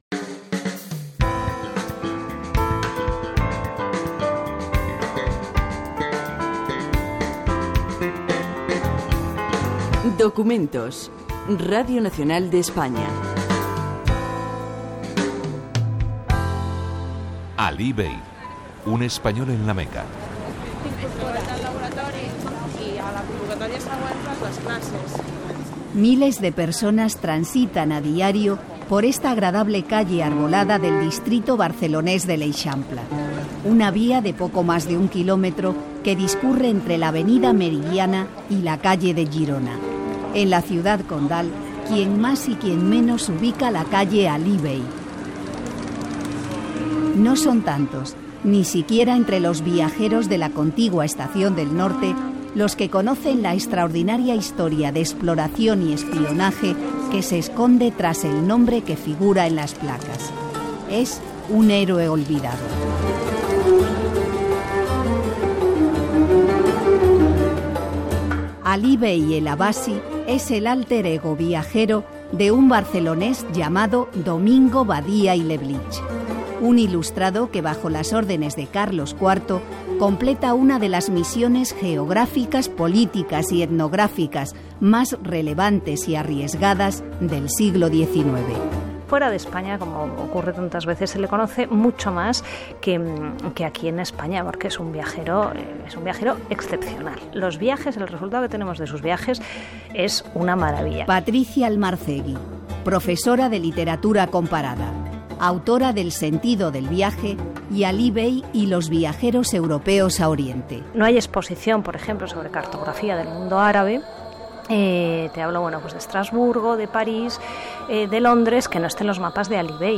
6cade06a594aaaaee53d56ccb76f391582d1d594.mp3 Títol Radio Nacional de España Emissora Radio Nacional de España Barcelona Cadena RNE Titularitat Pública estatal Nom programa Documentos Descripció Programa dedicat a Domènec Badia i Leblich (Barcelona, 1766 / 1 d'abril 1767) que fou un espia, arabista i aventurer català, conegut també com a Alí Bey ben Uthman al-Abbasí, que va ser el primer català que va arribar a La Meca.